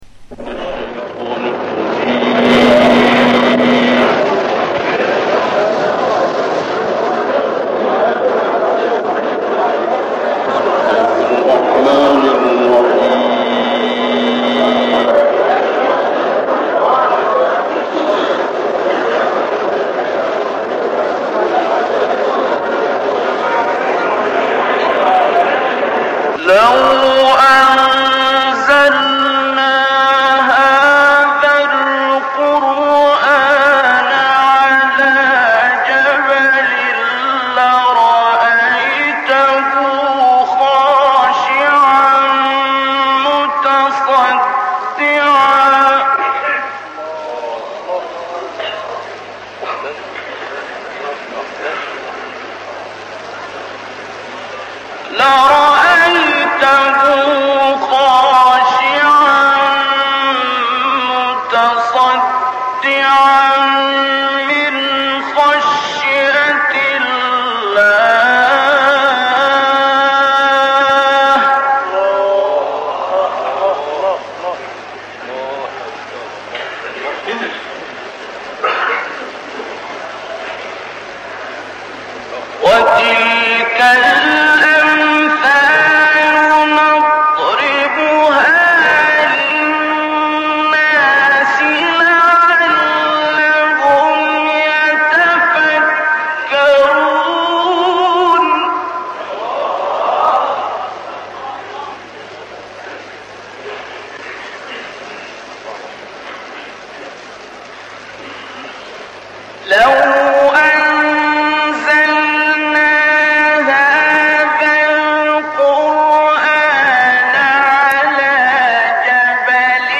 این تلاوت در عراق و در کاظمین اجرا شده است و خود استاد هم از این تلاوت به شایستگی یاد می‌کند.
به دلیل قدرت بالایی که در صوت خود داشت و طنین زیبای آن و این‌که صدای ایشان تمایلی به تِنور دارد، گاهی، برخی صدای ایشان را صبیانه یا کودکانه معرفی کردند.
در این تلاوت، عبدالباسط با مقام بیات آغاز کرده است و بیات نوا خوانده است و به سمت صبا انتقالاتی داشته و بعد به بخش چند بار تکرار خودش «لَوْ أَنْزَلْنَا»ی معروف رسیده است.
آیه 23 را تا «یشرکون» یک نفس اجرا می‌کند. حالت مقام بیات و صبا و تلوینی که ایشان در ترکیب دو مقام دارد، انجام می‌شود.